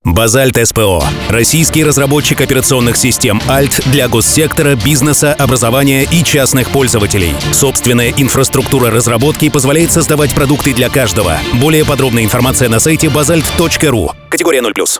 Примеры аудиороликов